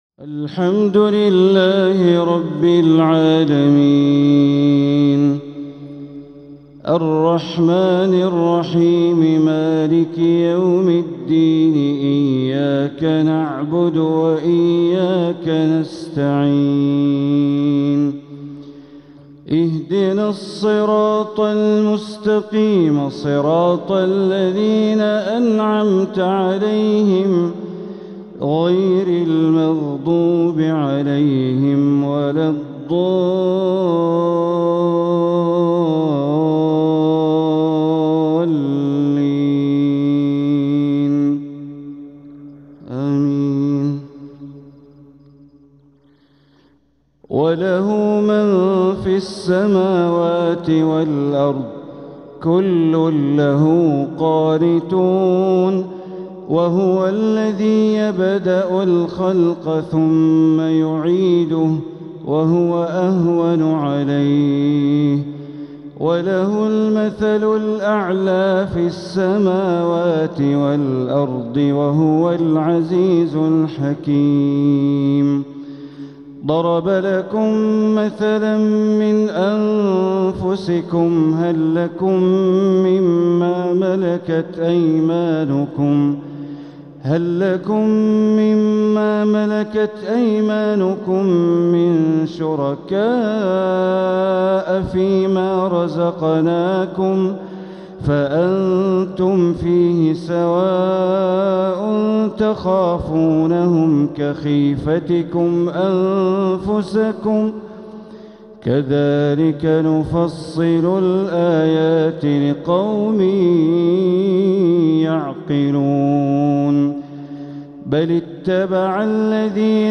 تلاوة من سورة الروم ٢٦-٦٠ | فجر الخميس ١٩ربيع الأول ١٤٤٧ > 1447هـ > الفروض - تلاوات بندر بليلة